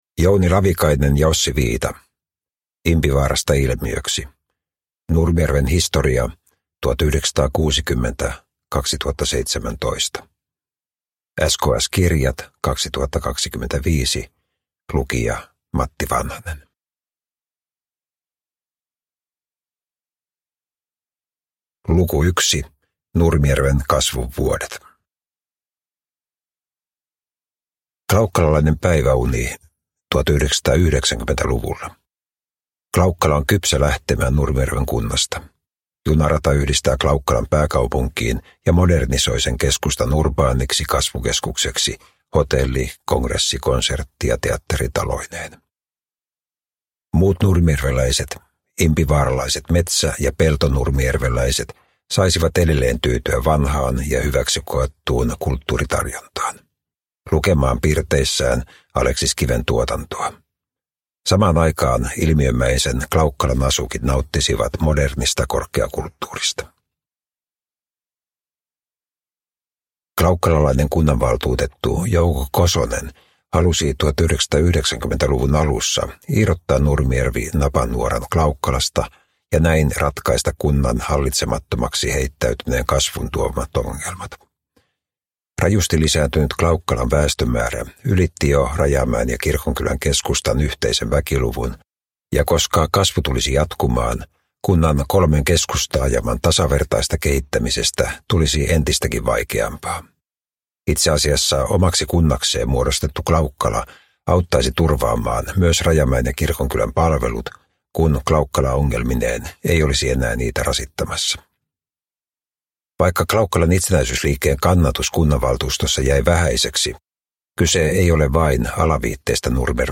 Impivaarasta ilmiöksi – Ljudbok
Uppläsare: Matti Vanhanen